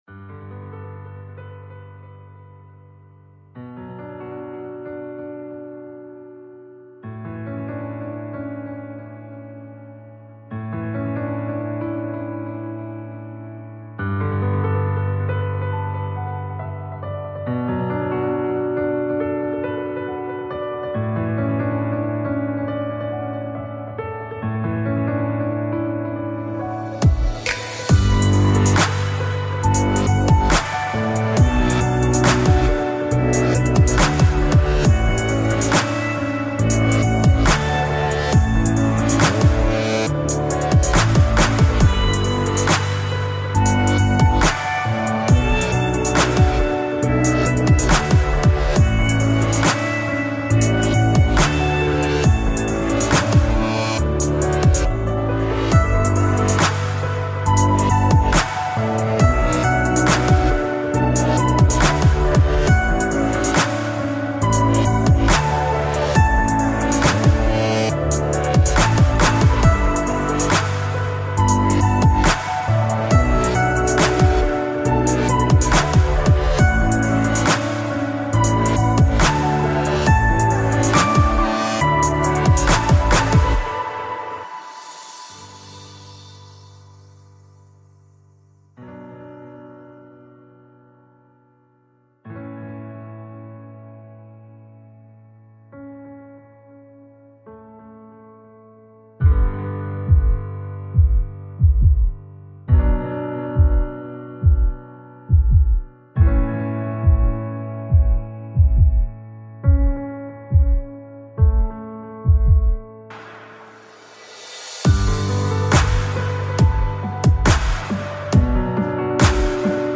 Зарубежная романтическая инструментальная музыка без слов